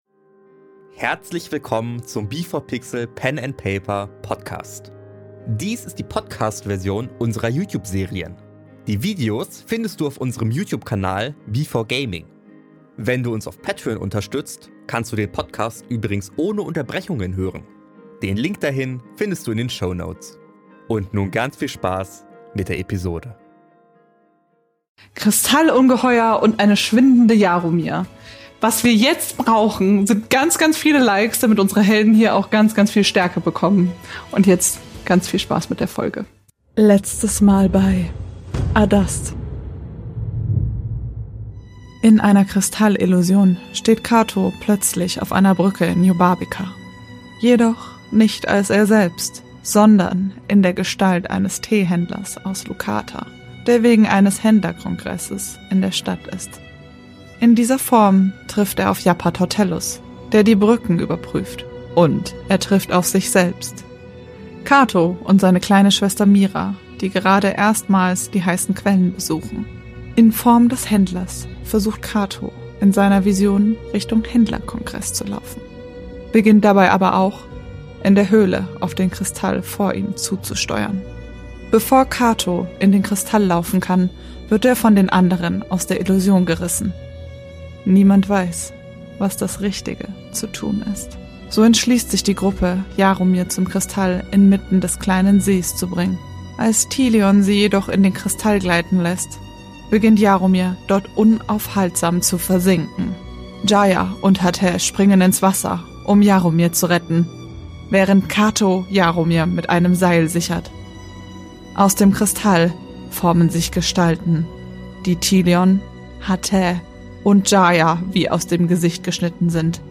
In dieser Serie entstehen aufgrund ihrer improvisatorischen Art immer wieder Situationen mit verschiedensten Themen und Inhalten, die in euch ungewollte Erinnerungen oder auch Gefühle hervorrufen können und generell schwerer zu verarbeiten sind.
Dies hier ist die Podcast-Version mit Unterbrechungen.